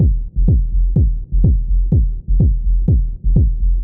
• house - techno beat passage 124bpm - Fm - 125.wav
A loop that can help you boost your production workflow, nicely arranged electronic percussion, ready to utilize and royalty free.